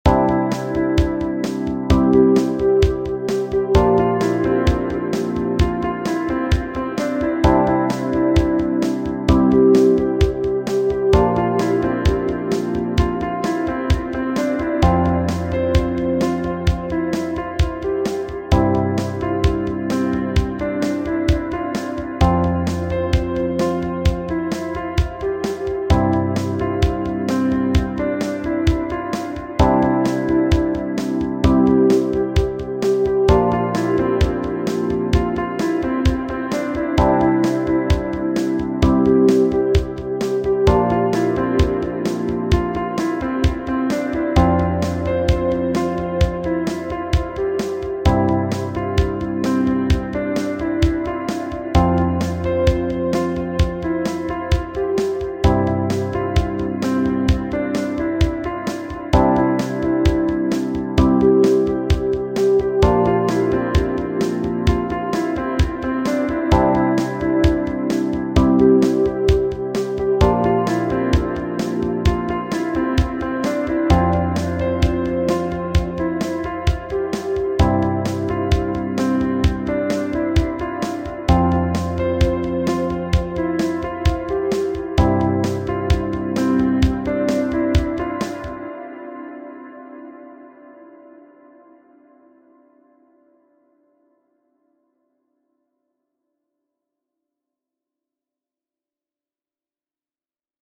Romance – Free Stock Music